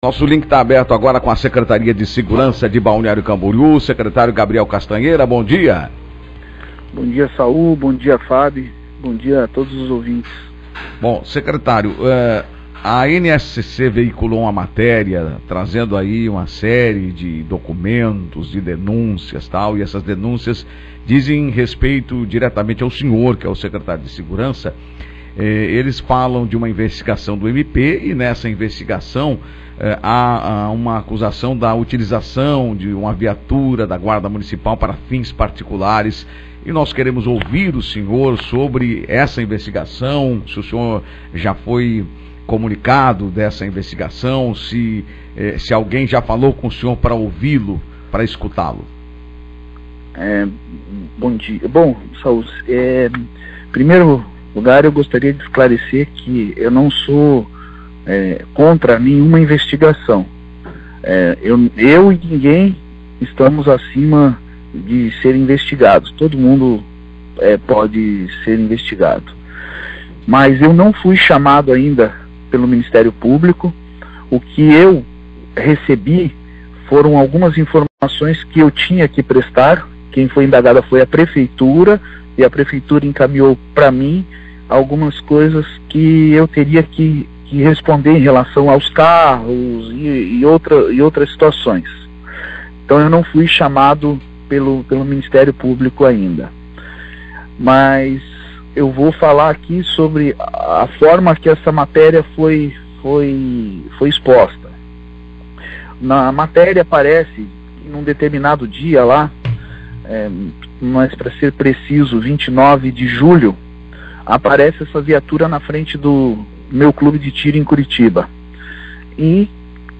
Sobre essa suspeita e a investigação do MP, o secretário Castanheira falou ao Jornal da Manhã, na rádio 105.9FM. Confira o que ele disse: Entrevista Secretário Castanheira